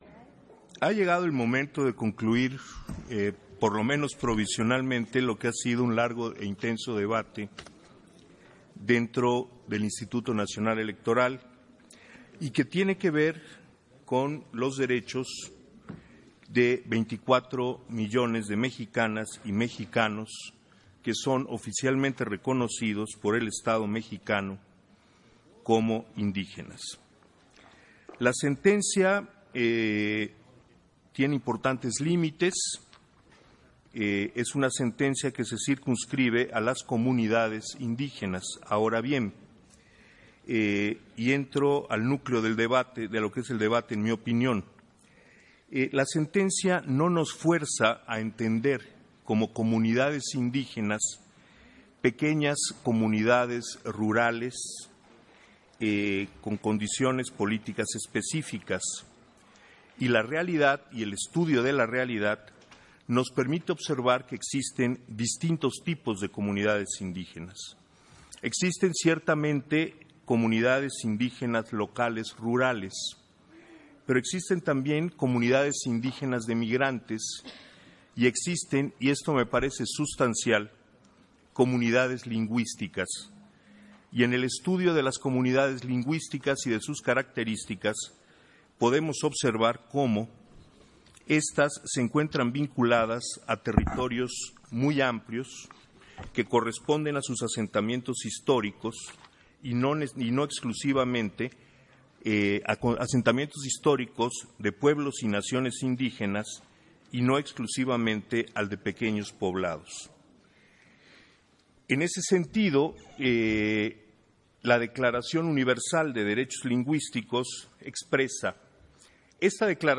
291122_AUDIO_INTERVENCIÓN-CONSEJERO-ESPADAS-PUNTO-30-SESIÓN-ORD.